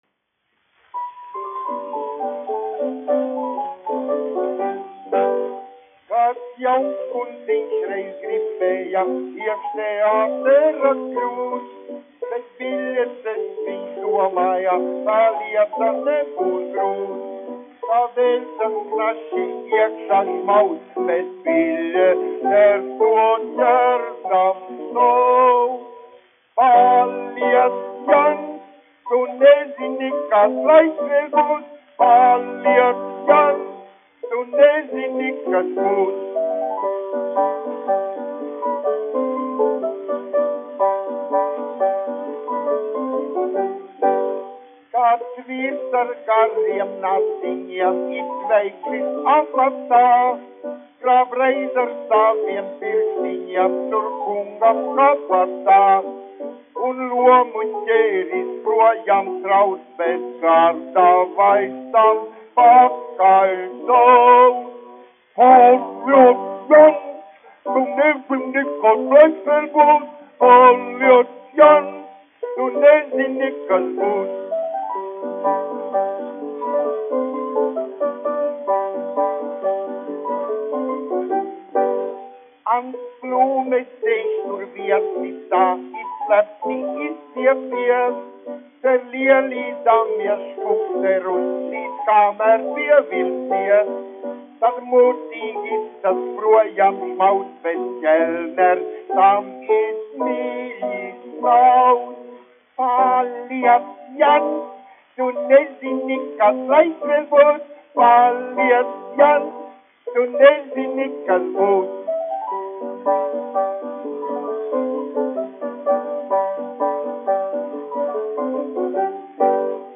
1 skpl. : analogs, 78 apgr/min, mono ; 25 cm
Humoristiskās dziesmas
Populārā mūzika
Skaņuplate
Latvijas vēsturiskie šellaka skaņuplašu ieraksti (Kolekcija)